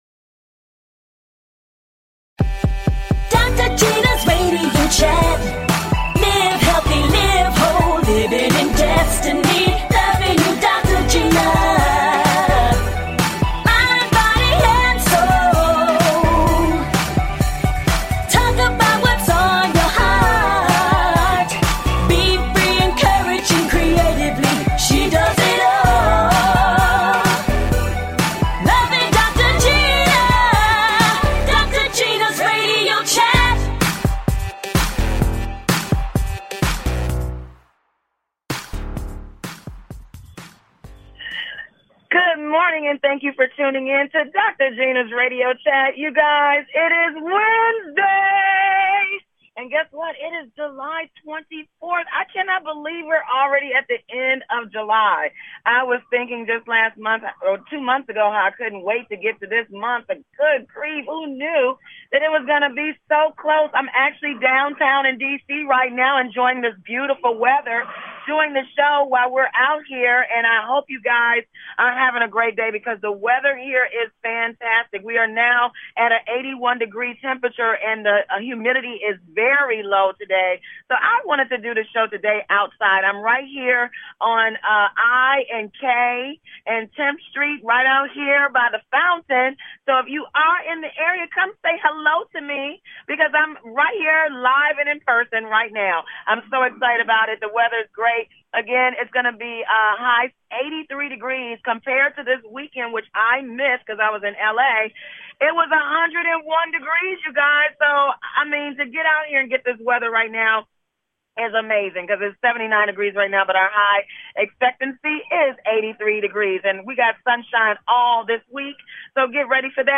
Guests, RnB Singer and Songwriter, Shirley Murdock and Comedian